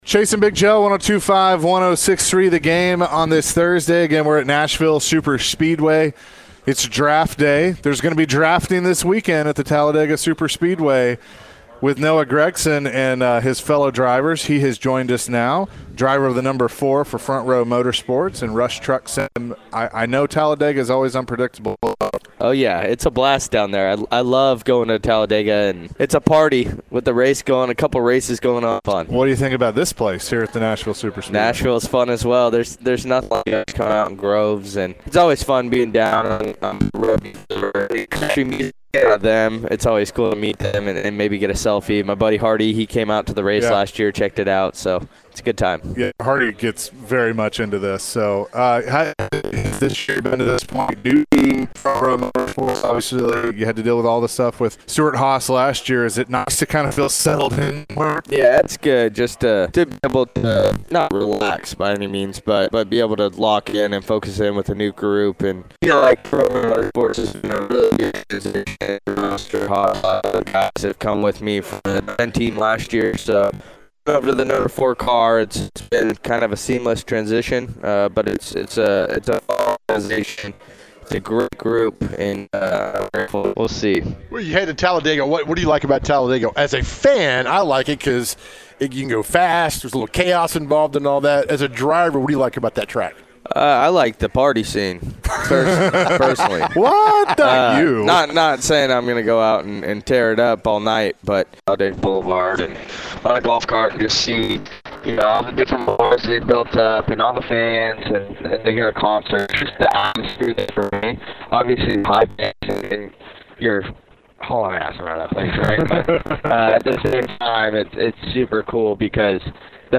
NASCAR Driver Noah Gragson joined the show. Noah discussed the in-depth analysis of driving in NASCAR. Plus, Noah mentioned his strategy when it comes to racing.